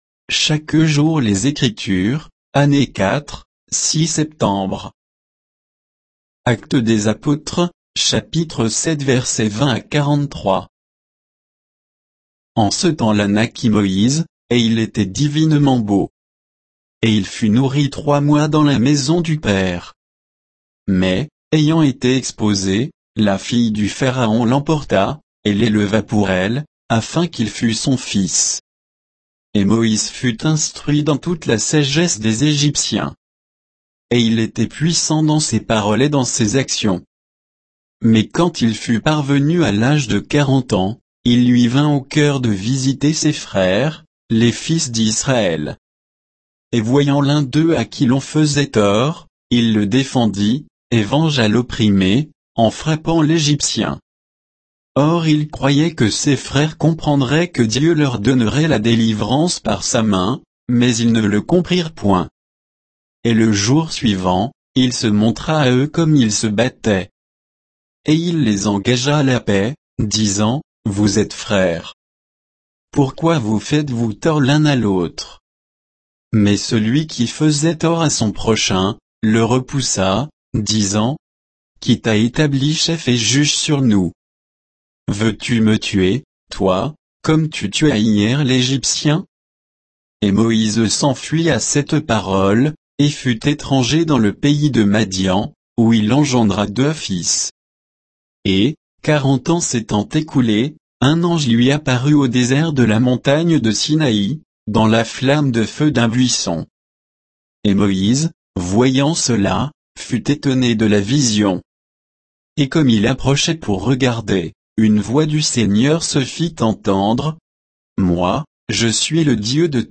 Méditation quoditienne de Chaque jour les Écritures sur Actes 7, 20 à 43